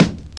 Snare (23).wav